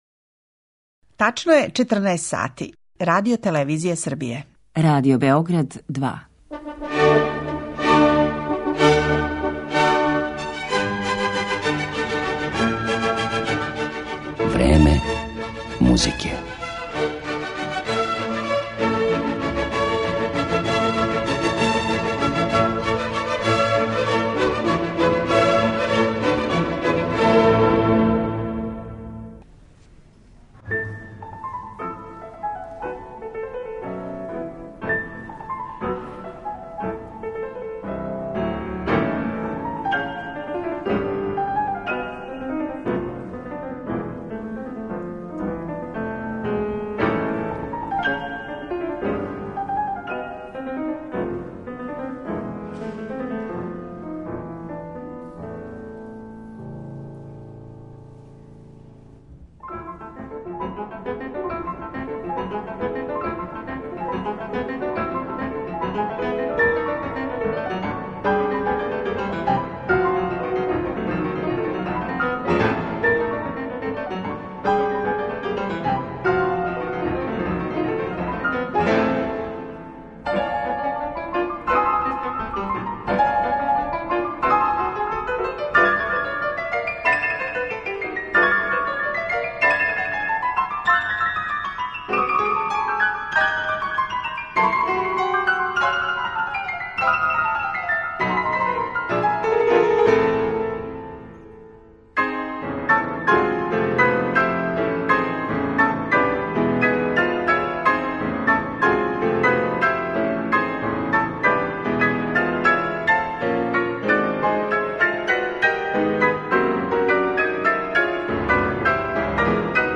амерички клавирски дуо